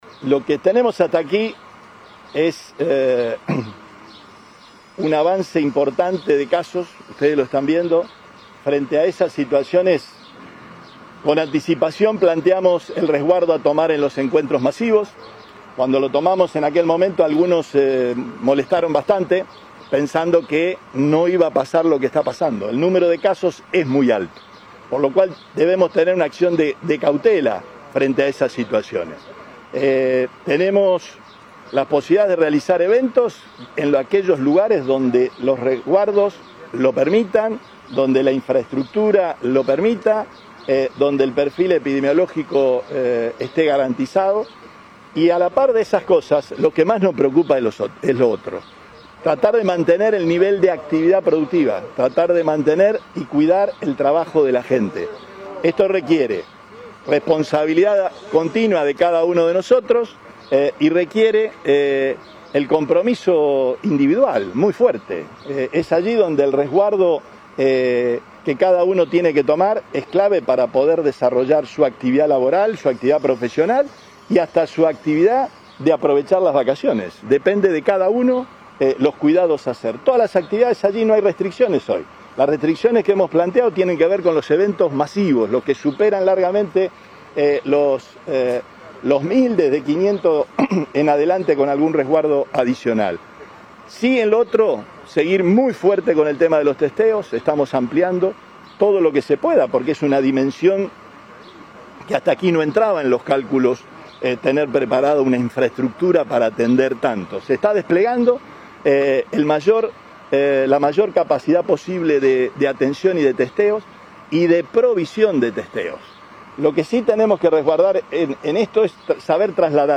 Durante la recorrida de la obra del desagüe pluvial Entubado Tucumán de la ciudad de Rafaela, el gobernador indicó que “tenemos un avance importante de casos.